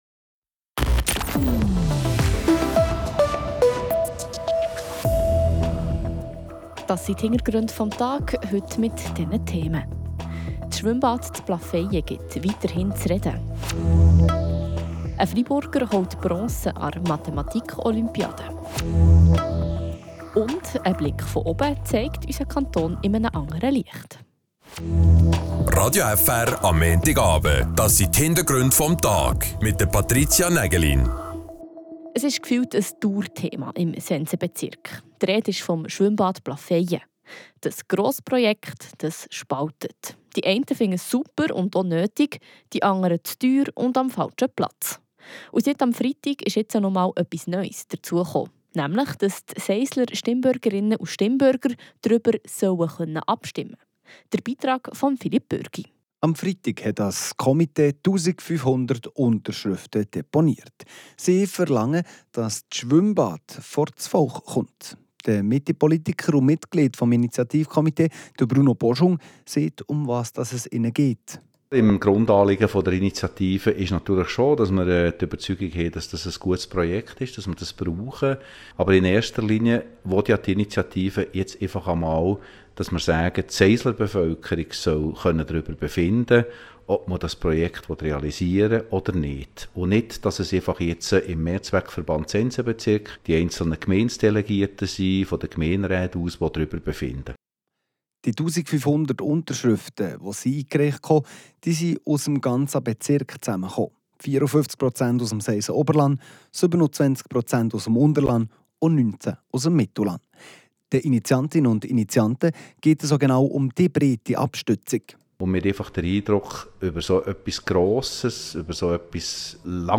Und eine Ballonfahrt über den Kanton zeigt Freiburg in einem anderen Licht. Wir waren im Korb, hoch über dem Freiburgerland.